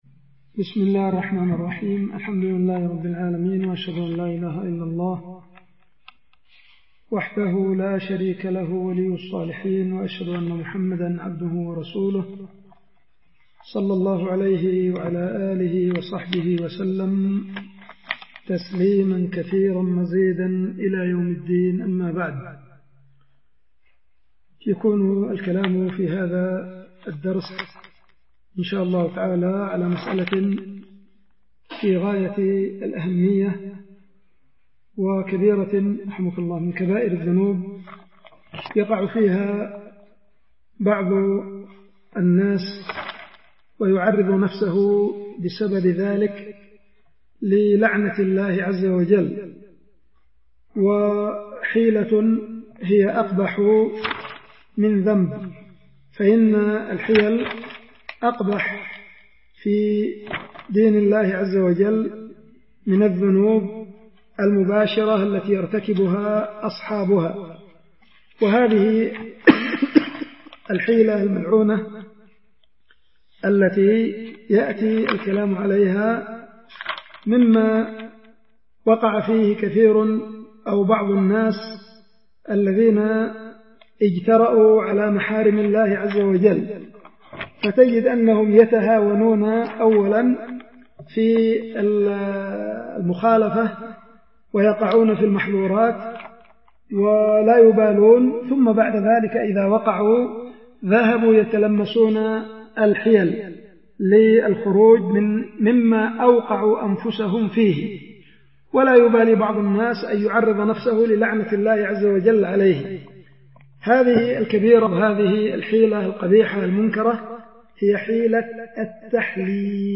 الدروس
القيت في دار الحديث ببعدان